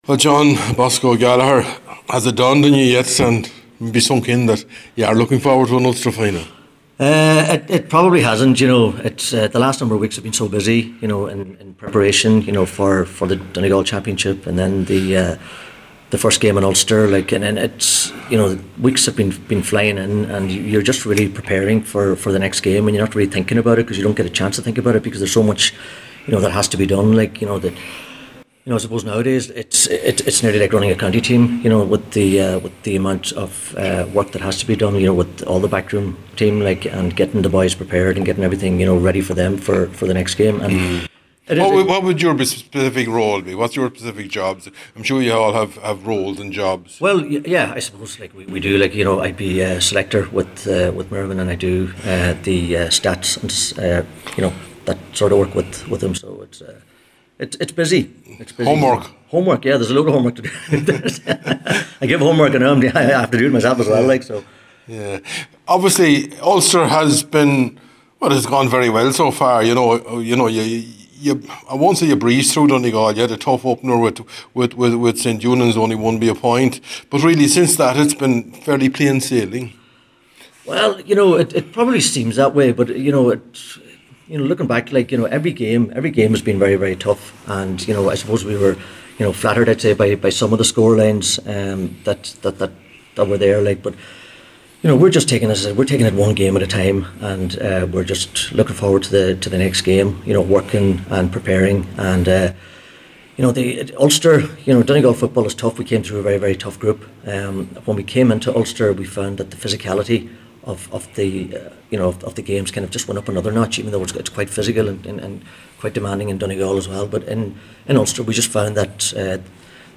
at the Ulster Final launch earlier in the week